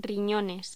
Locución: Riñones
Sonidos: Voz humana